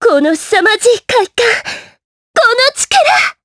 Erze-Vox_Victory_jp.wav